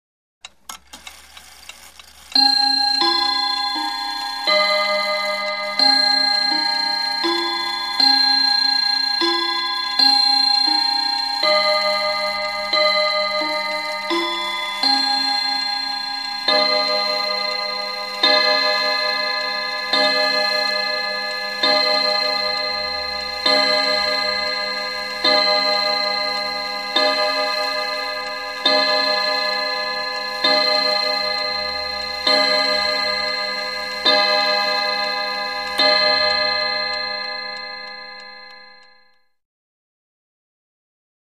Mantel Clock; Chime; Mantel Clock Strikes 12- Standard Musical Chime Series Into Strikes With Mechanical Clock Winding Movement Followed By A Short Series Of Tick Tocks. Close Perspective.